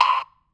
Tom1.wav